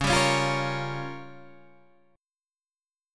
C# 6th Flat 5th